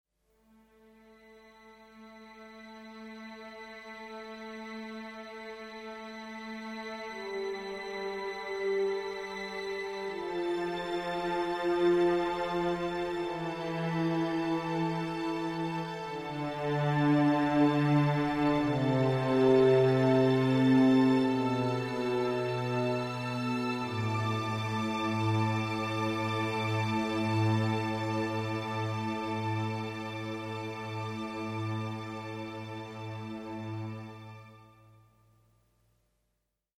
Cue 2 Strings